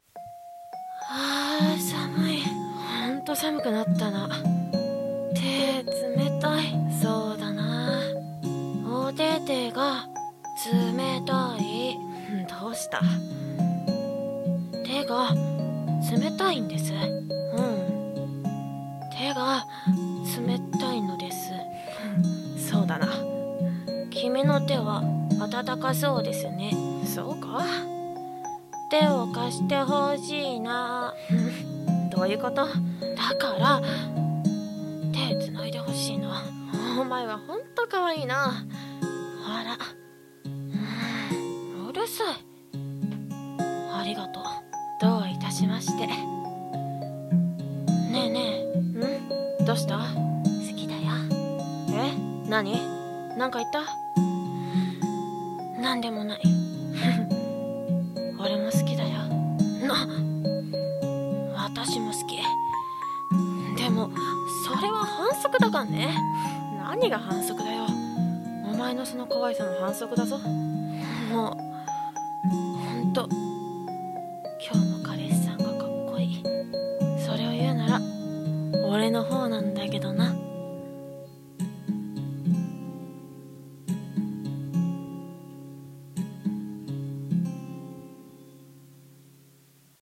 【二人声劇】手が繋ぎたい